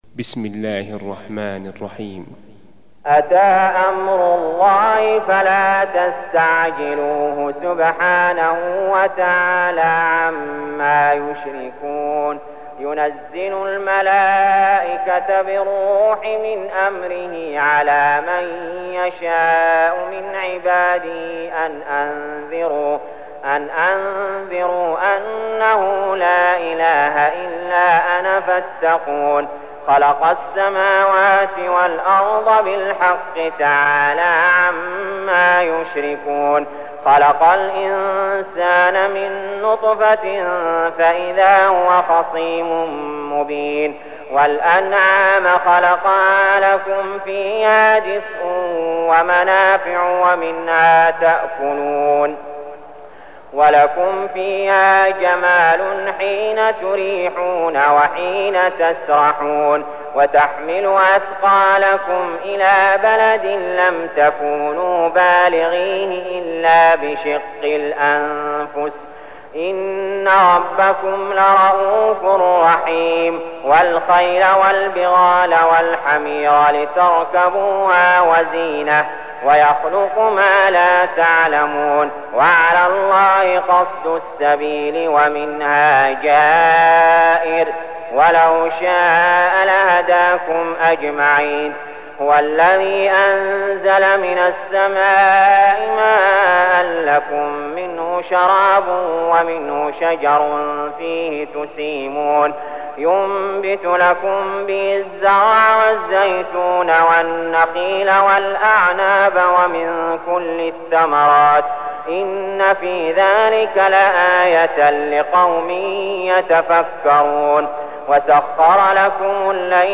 المكان: المسجد الحرام الشيخ: علي جابر رحمه الله علي جابر رحمه الله النحل